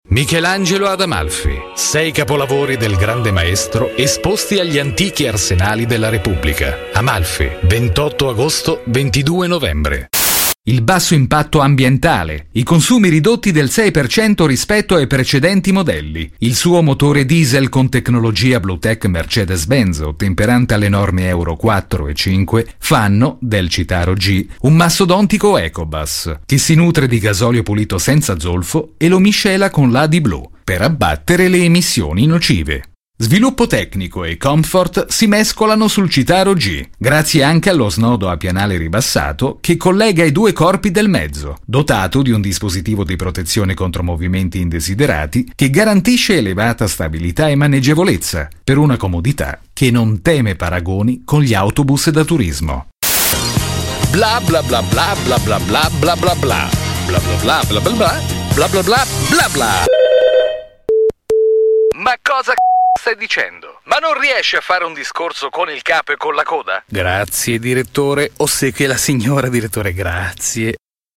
speaker radiofonico per emittenti regionali e superstation, spot, documentari.
voce dinamica, giovanile, istituzionale e all\'occorrenza confidenziale
Sprechprobe: Werbung (Muttersprache):